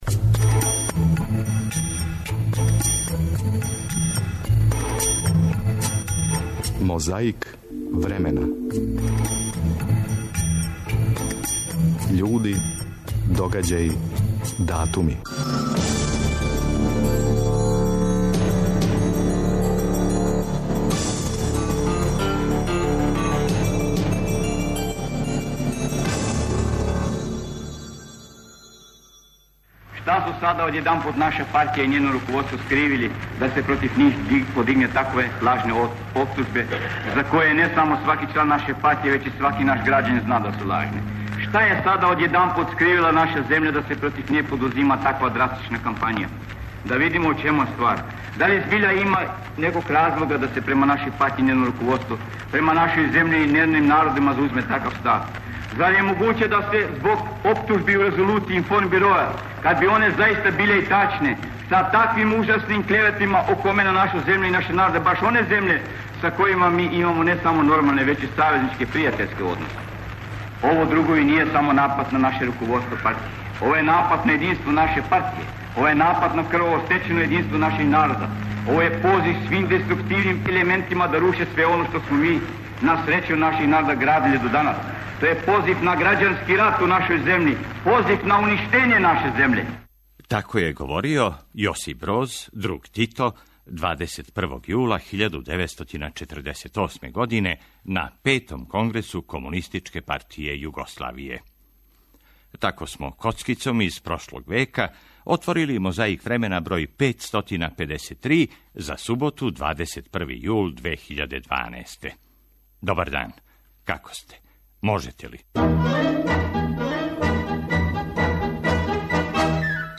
Борбу против пилећег памћења ове суботе отвара Јосип Броз Тито. Подсетићемо се како је говорио 21. јула 1948. на Петом конгресу КПЈ.
Подсећа на прошлост (културну, историјску, политичку, спортску и сваку другу) уз помоћ материјала из Тонског архива, Документације и библиотеке Радио Београда.